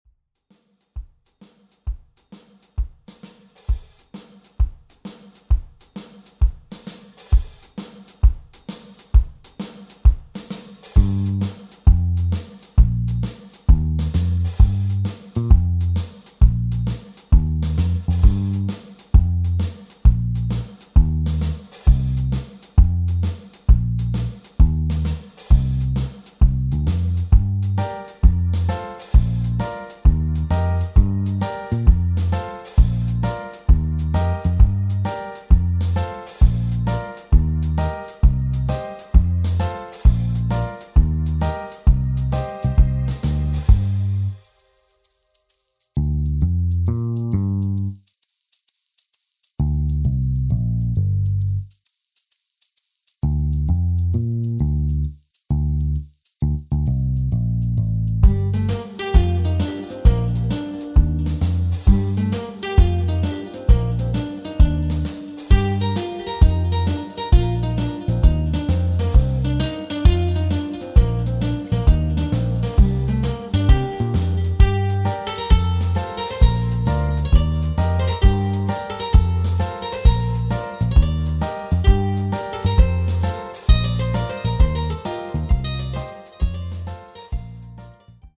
• Das Instrumental beinhaltet NICHT die Leadstimme